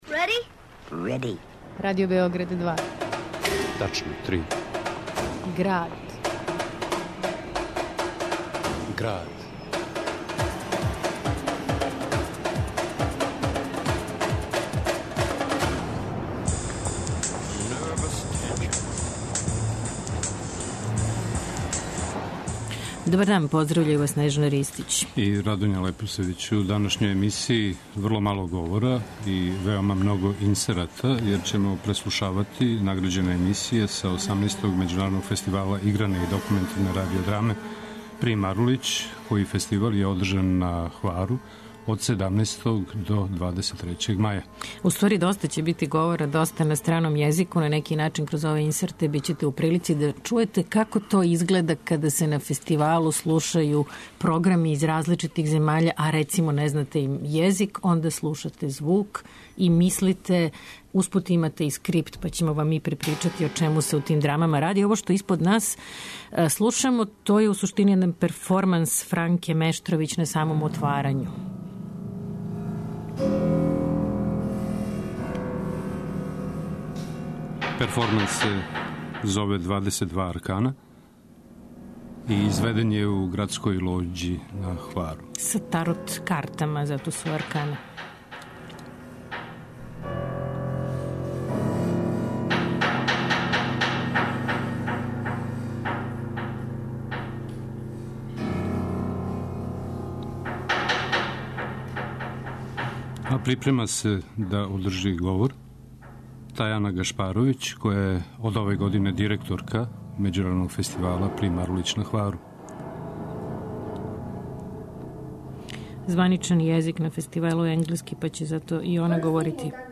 U Gradu : inserti iz nagrađenih programa i priča o Festivalu...